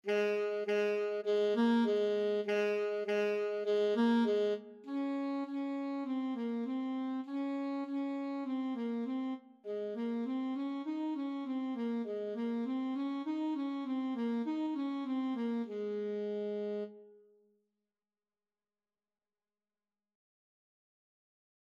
Free Sheet music for Alto Saxophone
2/4 (View more 2/4 Music)
Ab4-Eb5
Saxophone  (View more Beginners Saxophone Music)
Classical (View more Classical Saxophone Music)